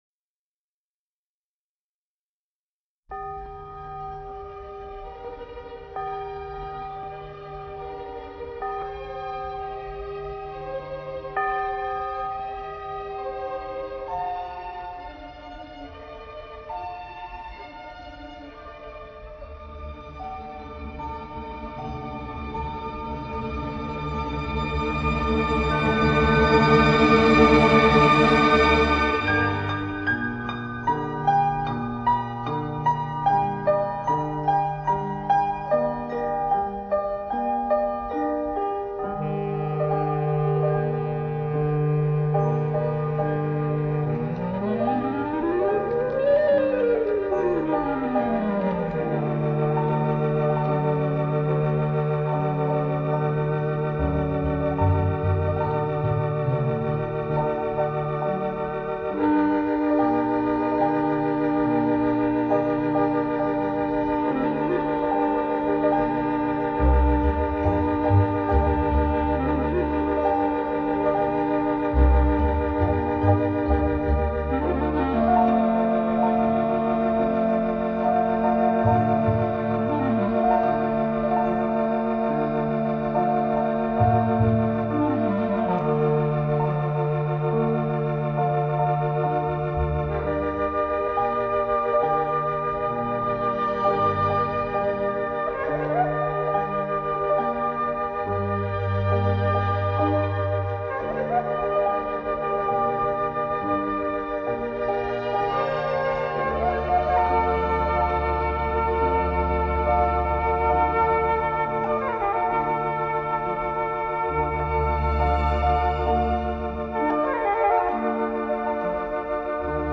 木管乐器是这支曲子的主要音色之一，在开始和结尾渲染悲凉忧伤的气质。